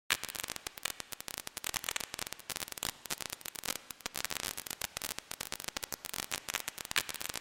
我的声音 " 噼里啪啦
描述：在FL工作室使用HARMOR制作
Tag: 噼啪作响 壁炉 火花 嘶嘶声 火焰 篝火 火星 流行 裂纹 烧伤 炉子 燃烧 火焰 嘶嘶声